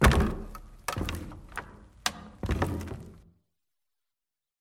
amb_fs_stumble_wood_12.mp3